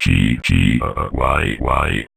VVE1 Vocoder Phrases
VVE1 Vocoder Phrases 20.wav